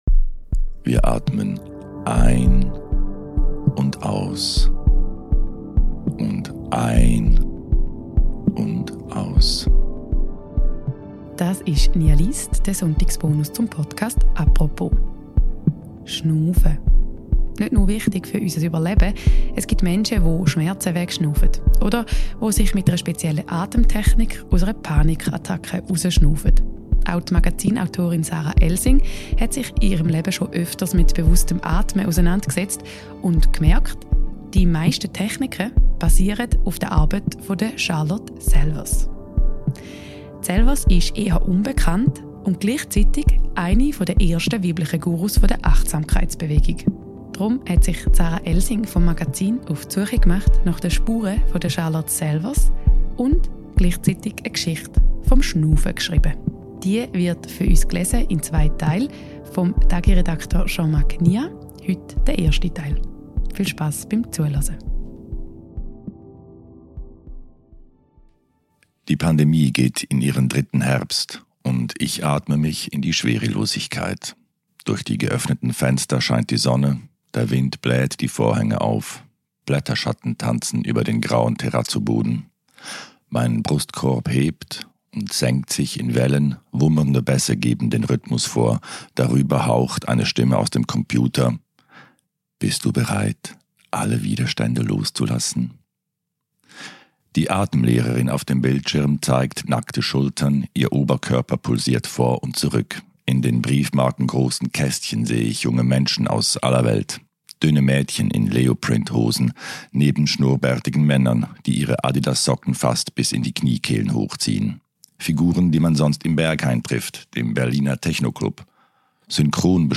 Entstanden ist eine kleine Geschichte des Atmens. Gelesen wird der erste Teil des Textes